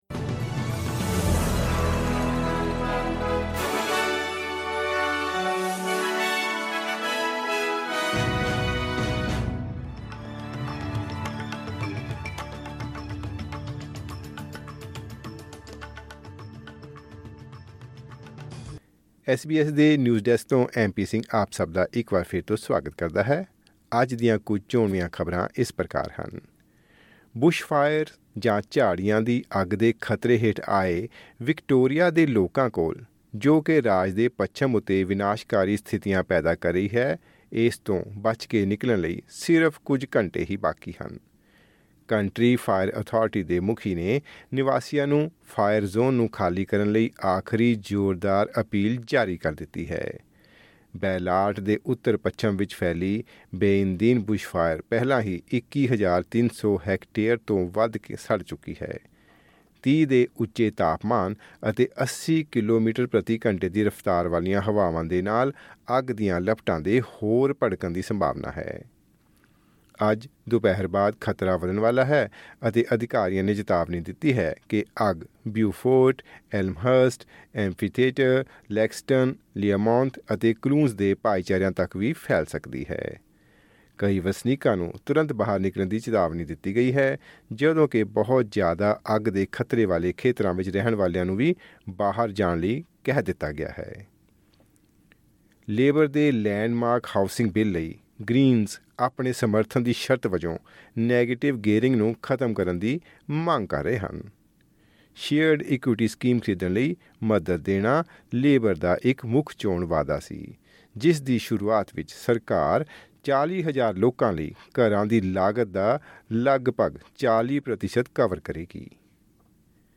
ਐਸ ਬੀ ਐਸ ਪੰਜਾਬੀ ਤੋਂ ਆਸਟ੍ਰੇਲੀਆ ਦੀਆਂ ਮੁੱਖ ਖ਼ਬਰਾਂ: 28 ਫਰਵਰੀ, 2024